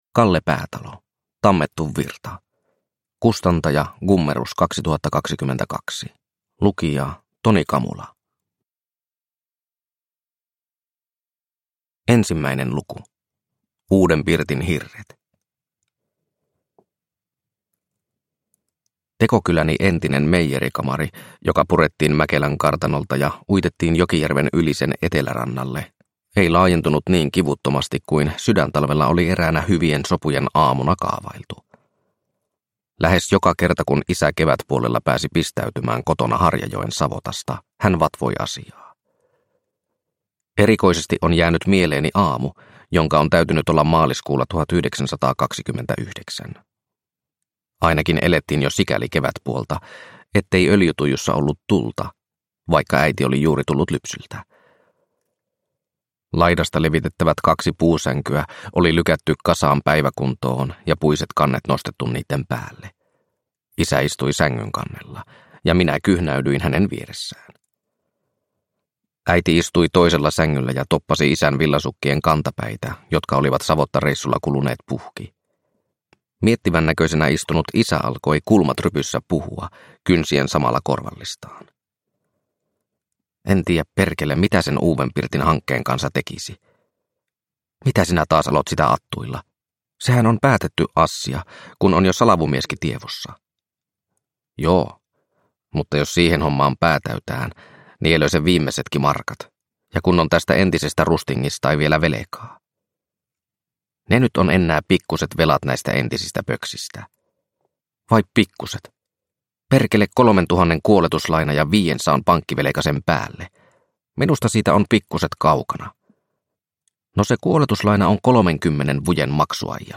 Tammettu virta – Ljudbok – Laddas ner